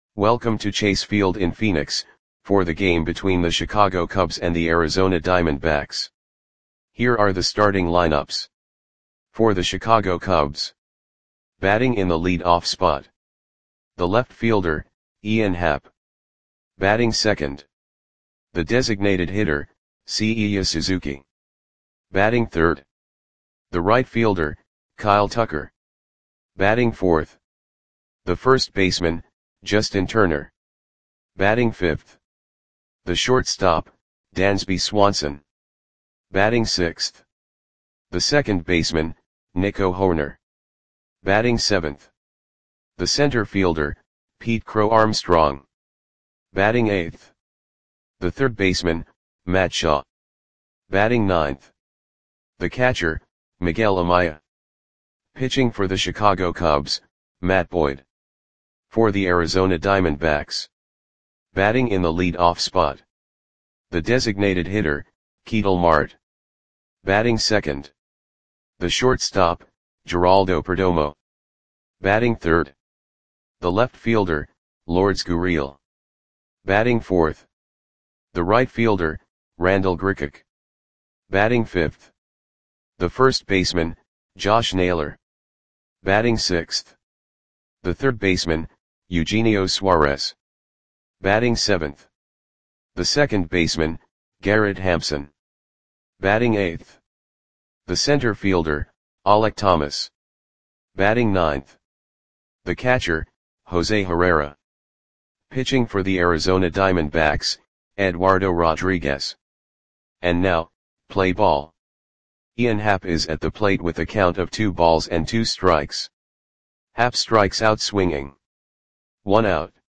Audio Play-by-Play for Arizona Diamondbacks on March 30, 2025
Click the button below to listen to the audio play-by-play.